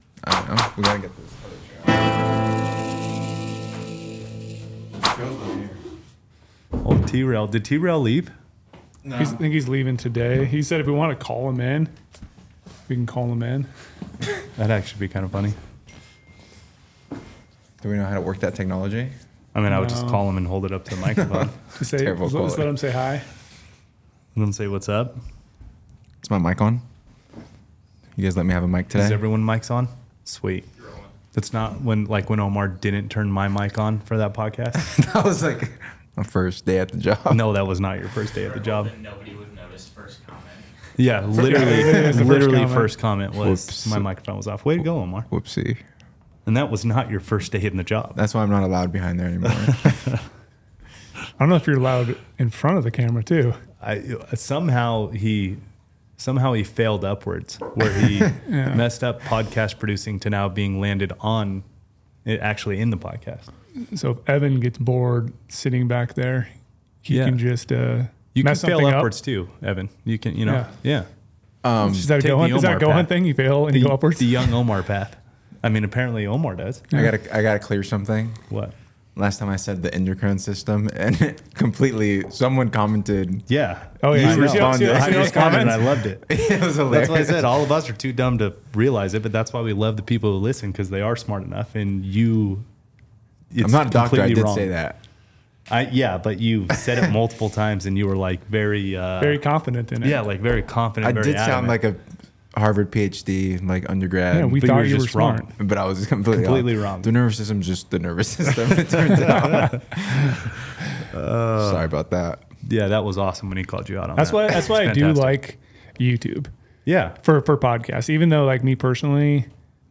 They dive into gear changes they have made leading up to the hunt and the importance of visualizing success and what that means to them. Lastly… They had a special guest call in for a brief segment.